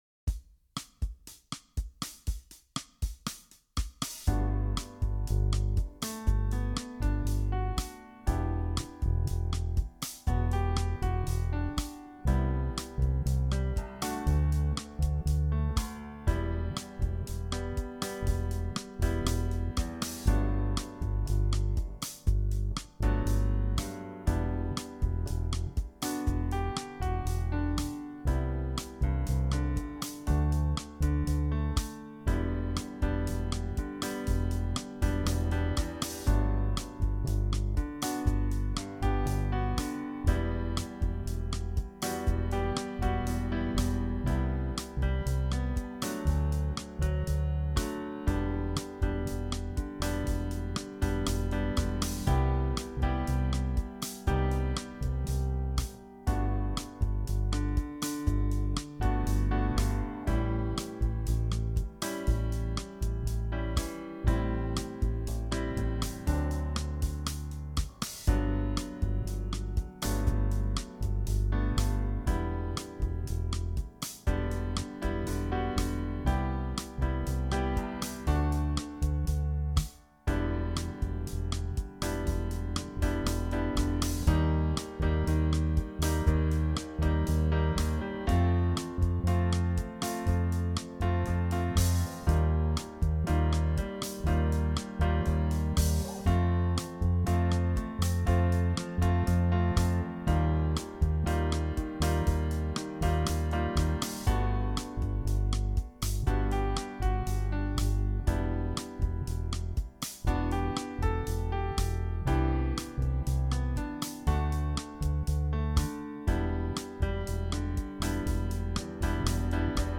Jam Track